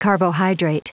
Prononciation du mot carbohydrate en anglais (fichier audio)
carbohydrate.wav